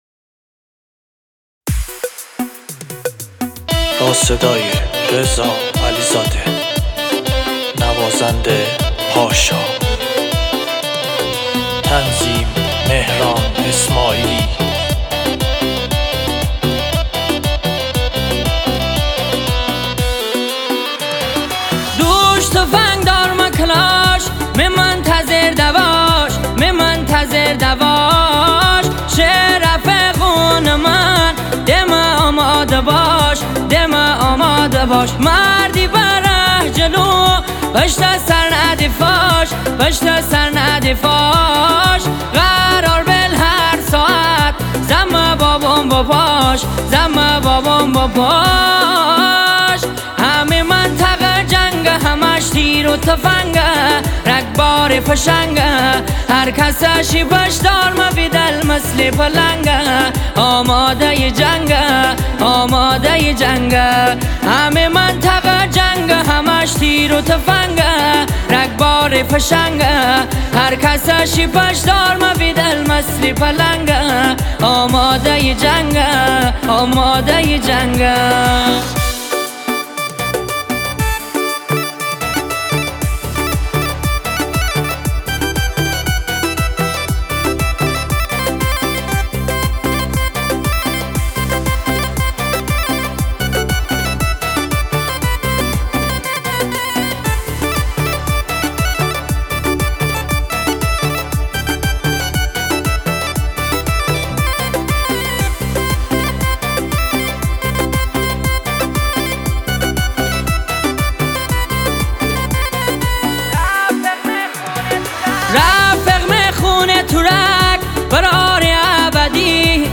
ریمیکس مازندرانی شاد لاتی